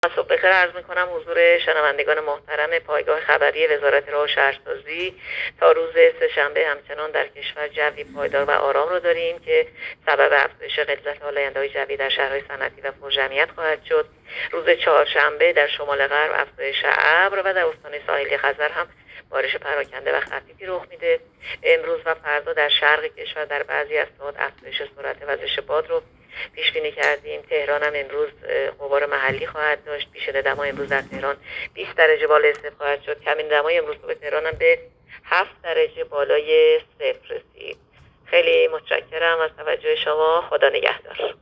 گزارش رادیو اینترنتی پایگاه‌ خبری از آخرین وضعیت آب‌وهوای اول آذر؛